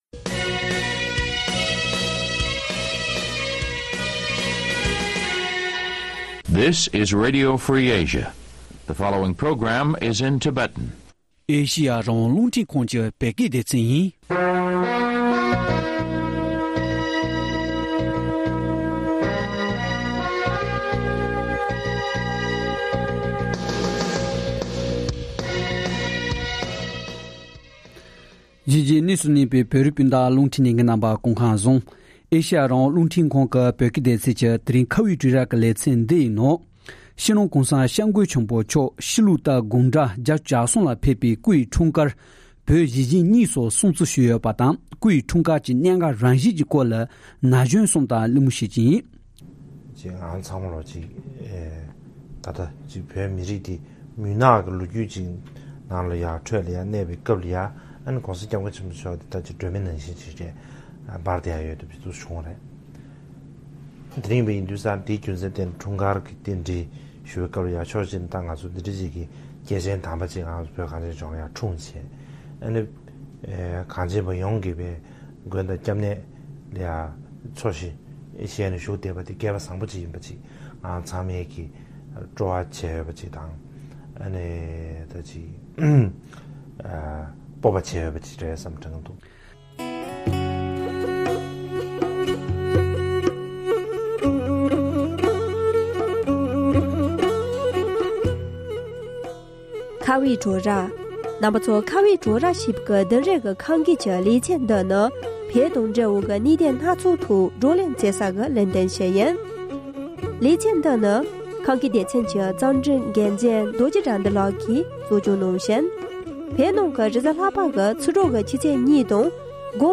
ན་གཞོན་གསུམ་དང་གླེང་མོལ་བྱེད་ཀྱི་ཡིན།